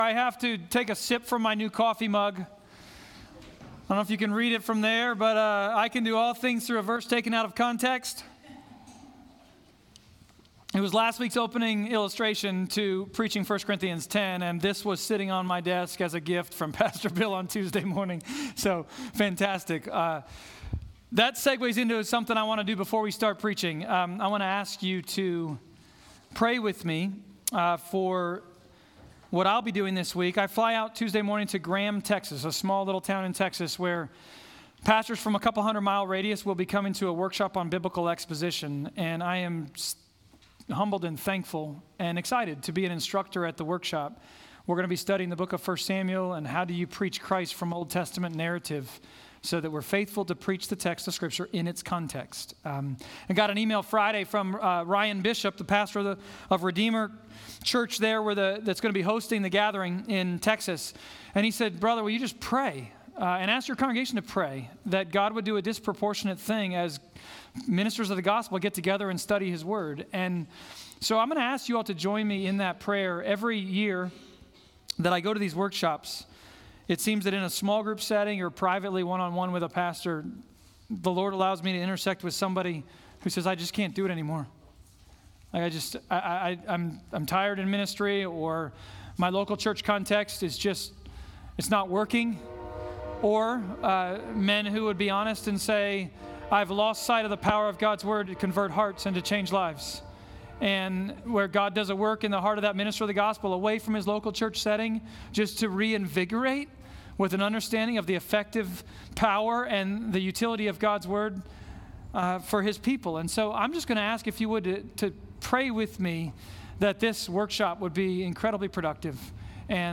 1 Corinthians Passage: 1 Corinthians 10.14-22 Service Type: Sermons « We are not that EXCEPTIONal.